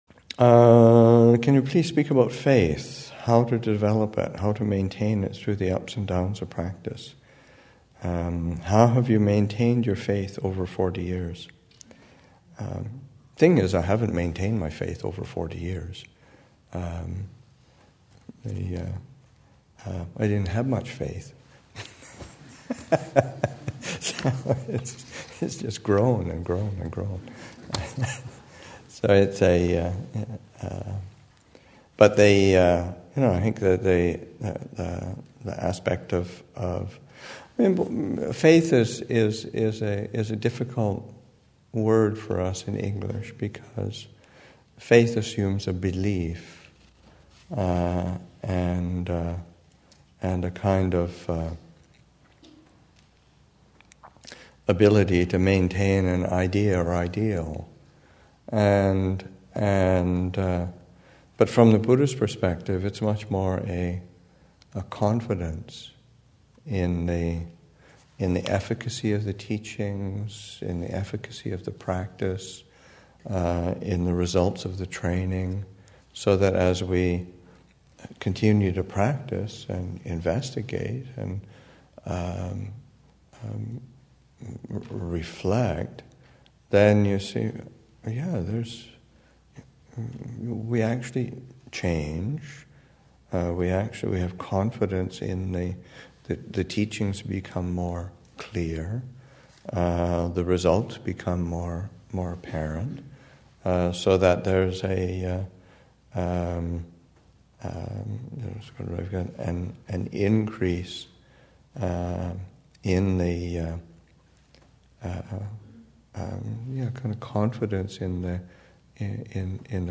2015 Thanksgiving Monastic Retreat, Session 8 – Nov. 28, 2015